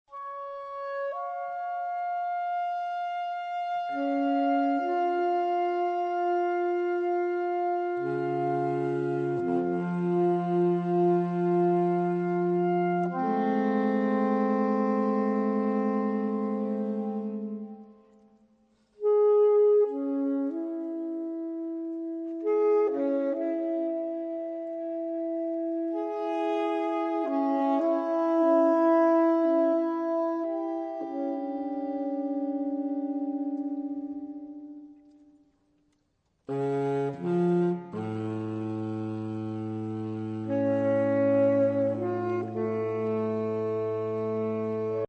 saxofon
Obsazení: 4 Saxophone (SATBar)
Diese moderne Exkursion mit improvisierten Solos für alle